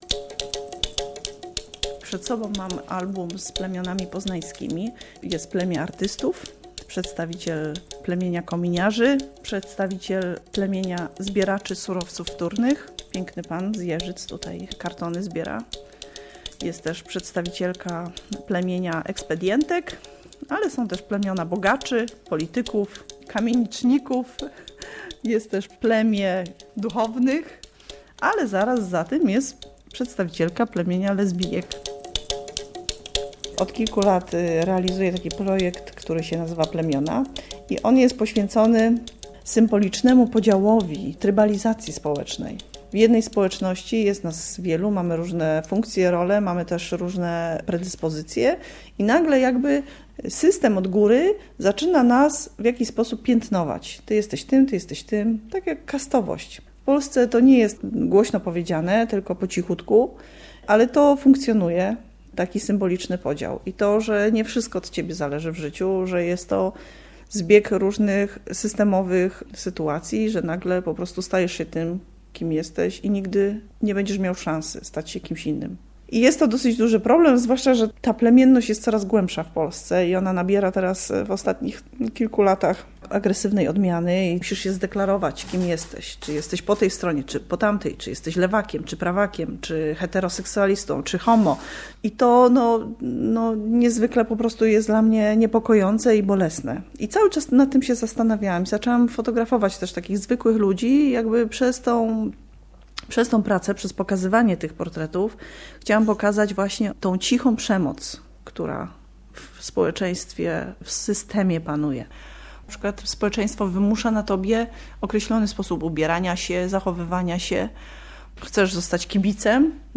Zetnij wysokie drzewa - reportaż